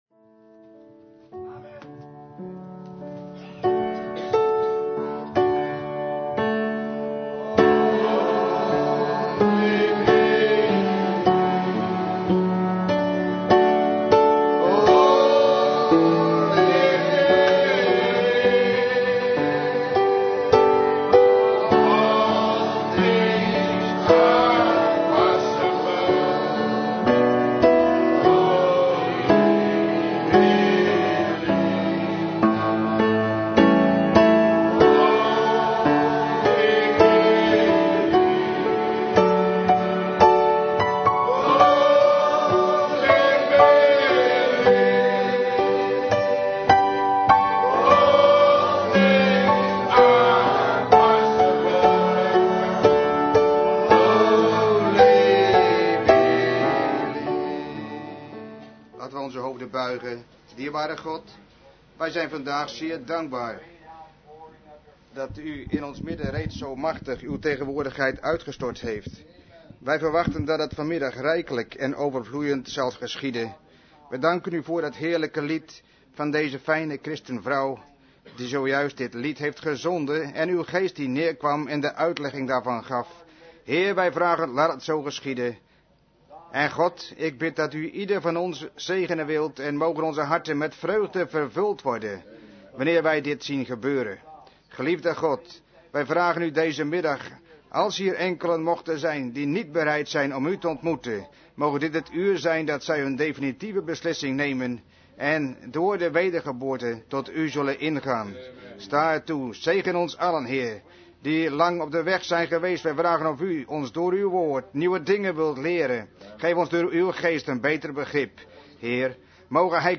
De vertaalde prediking "Birth pains" door William Marrion Branham gehouden in Ramada inn, Phoenix, Arizona, USA, 's middags op zondag 24 januari 1965